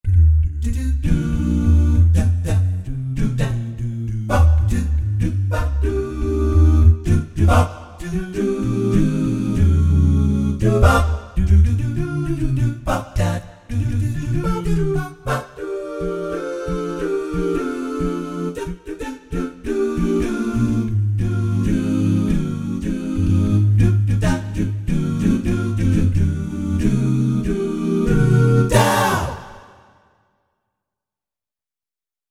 Jazz Combo 1 demo =3-A01.mp3